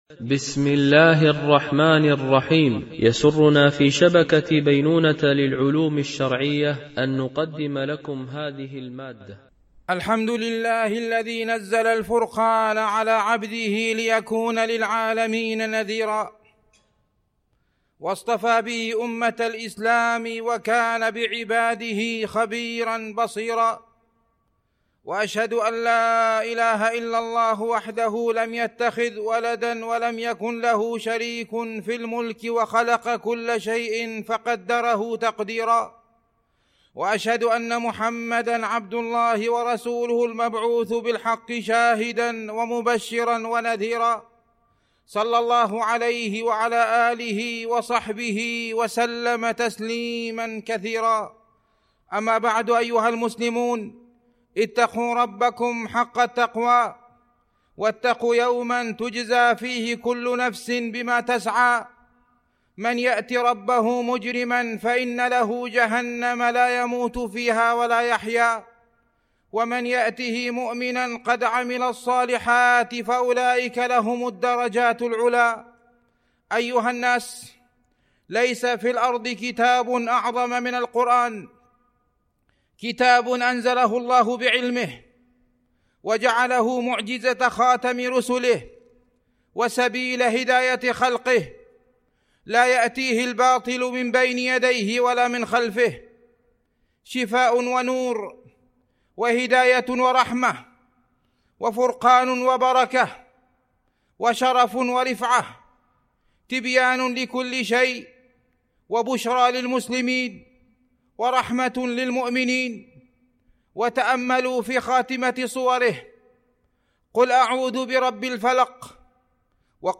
القسم: الرقية الشرعية
الأدعية والأذكار القسم: التفسير حمل الملف الصوتي حمّله